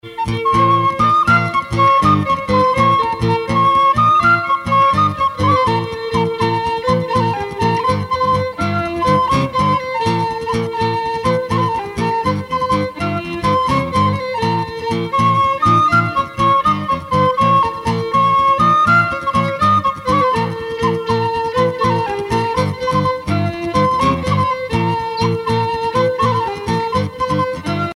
circonstance : cantique
Genre laisse
Pièce musicale éditée